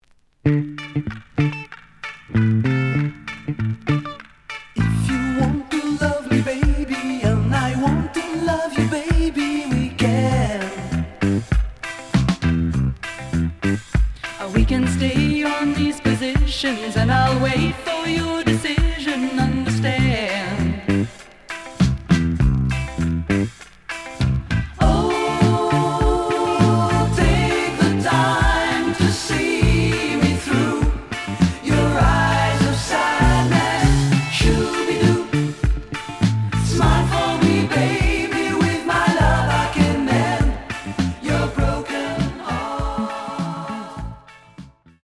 試聴は実際のレコードから録音しています。
●Genre: Disco
EX-, VG+ → 傷、ノイズが多少あるが、おおむね良い。